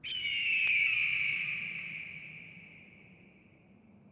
Fx [Western].wav